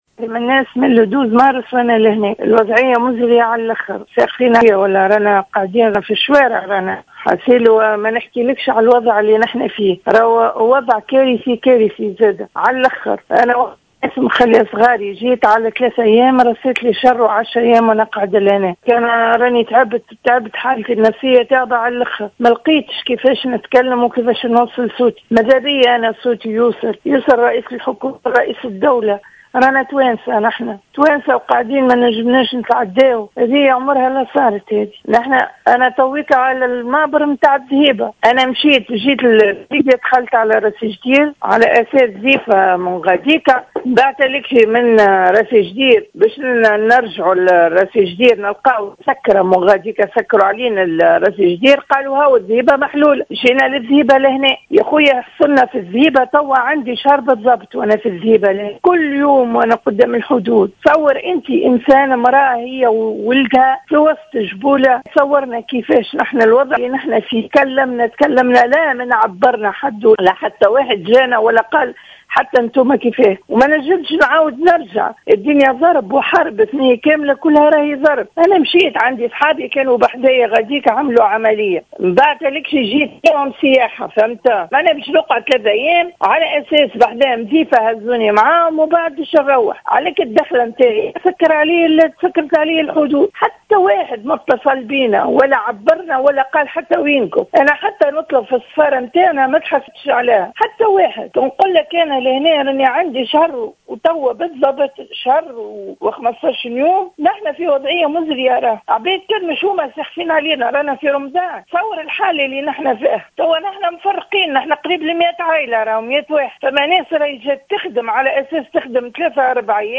من معبر الذهيبة الحدودي: سيّدة تونسية توجه نداء استغاثة لرئيسيْ الجمهورية و الحكومة
و قالت السيّدة في اتصال هاتفي بالجوهرة أف أم، إنها فشلت في التواصل مع الجهات الرسمية التونسية، التي لم تبادر بالاتصال بالجهات اللبيية، و التقصي حول وضعيتهم والظروف المزرية التي يعيشون فيها منذ أكثر من شهر حسب تعبيرها، مضيفة أنّ حوالي 100 شخص بينهم عائلات، مازالوا عالقين في المعبر الحدودي، و مصيرهم مجهول، في انتظار تدخل السلطات التونسية و إجلائهم في أقرب وقت ممكن.